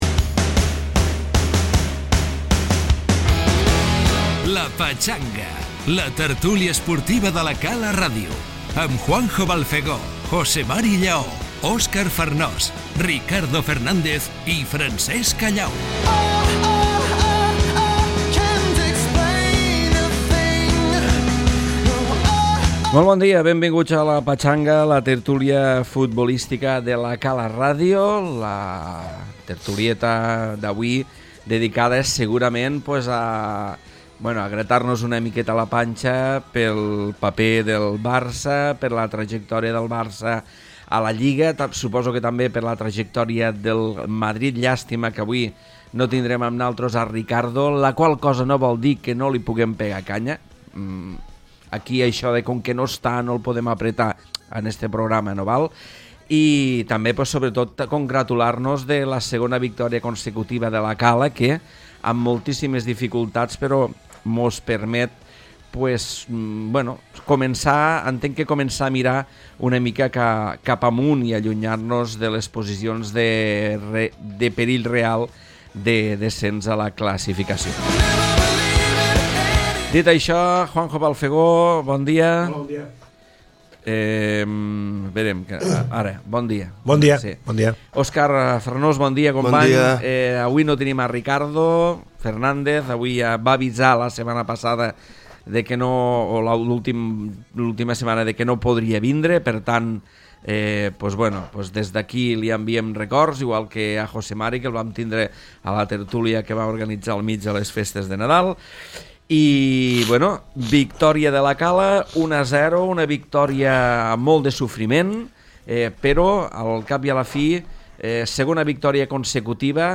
Victòria per la mínima de La Cala, lideratge del Barça i el Madrid a 19 punts… Tot això i més a la tertúlia futbolística dels dilluns!